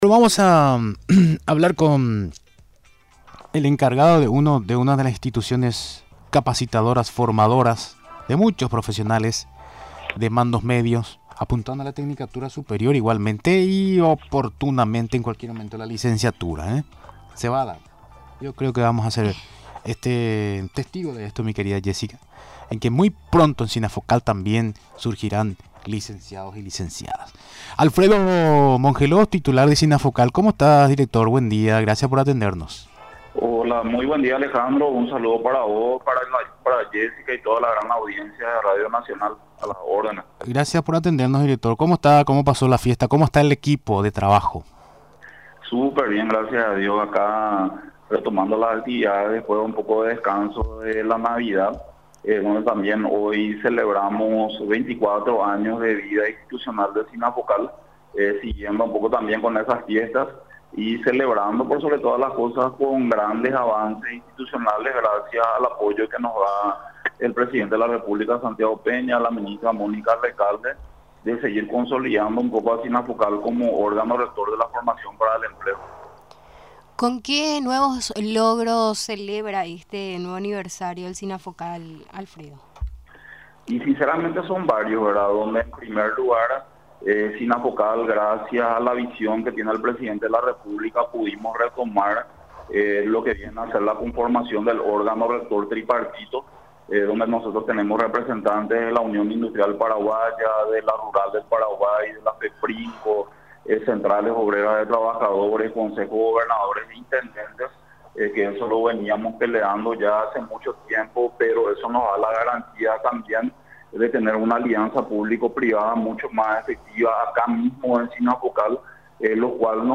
Durante la entrevista en Radio Nacional del Paraguay, destacó como logros de este año, tales como las tareas de manera conjunta, desarrolladas con la Unión Industrial Paraguaya, el sector empresarial, la Asociación Rural del Paraguay, gobernaciones y municipios.